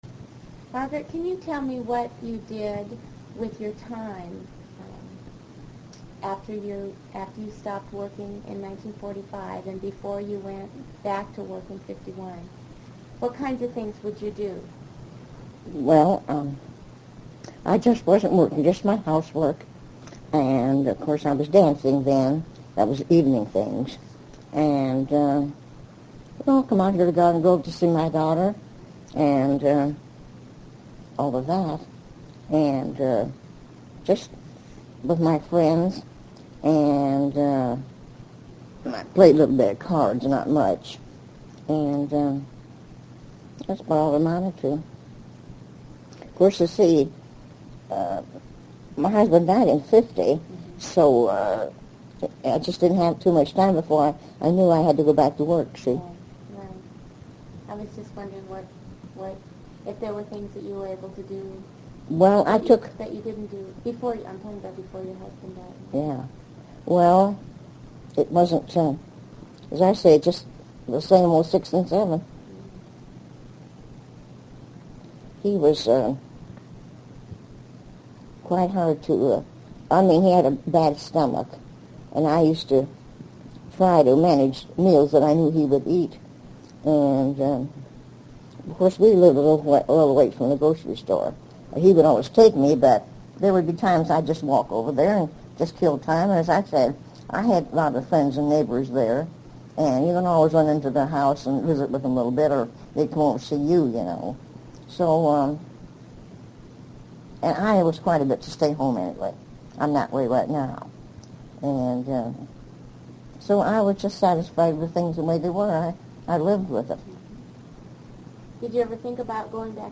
INTERVIEW DESCRIPTION